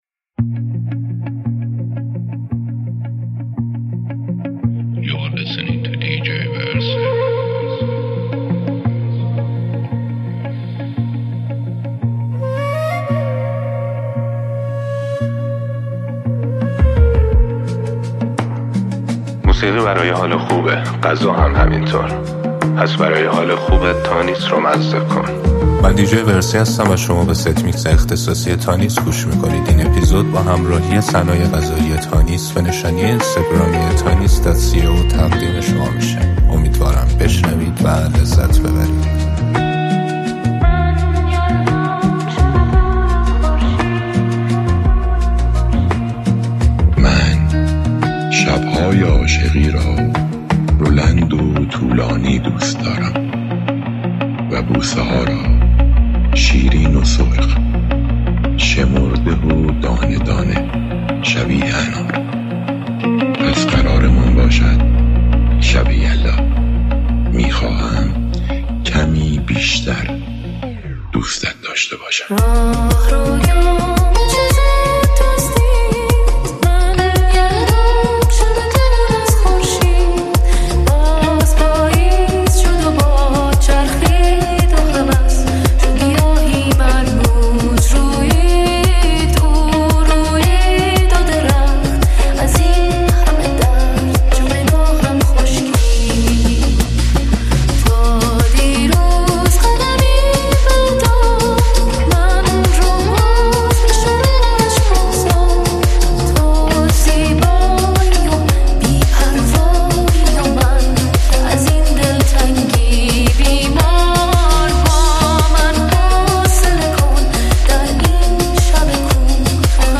اثری احساسی و دلنشین